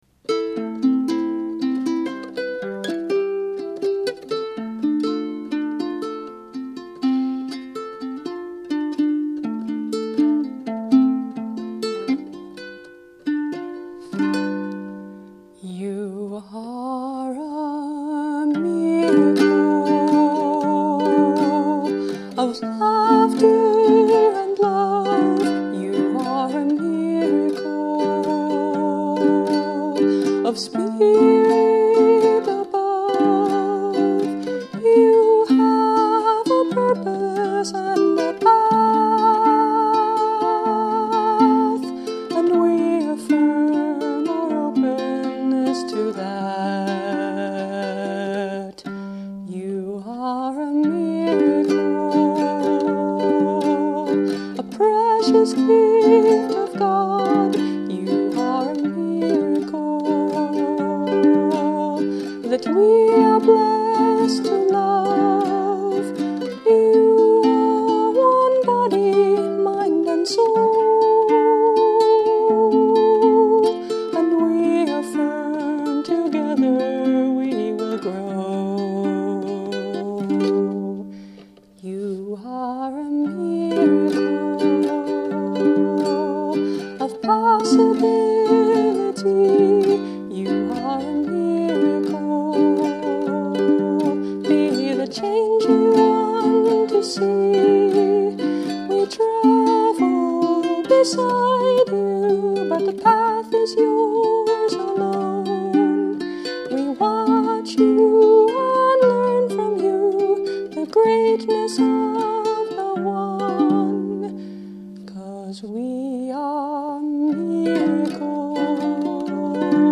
Lanikai LU21C concert ukulele